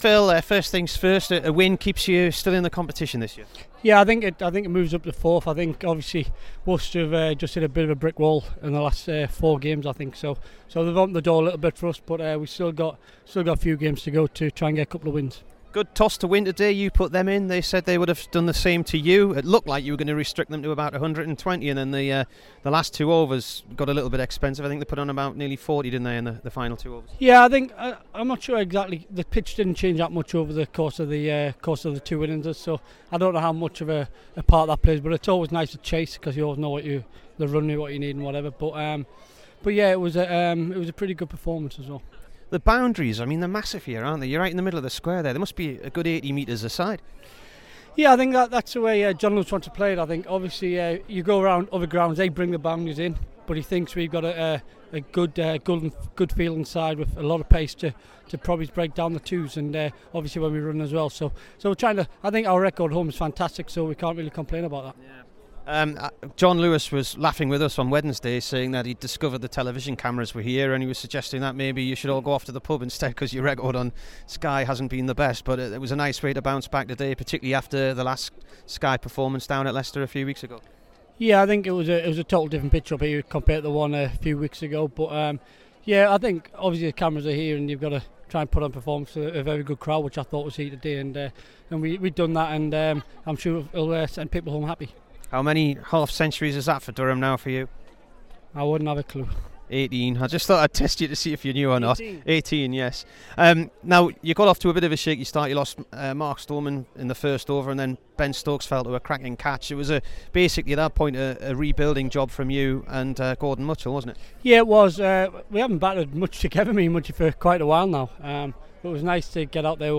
PHIL MUSTARD AND SCOTT BORTHWICK INT